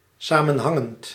Ääntäminen
US : IPA : [kəˈnɛktɪd]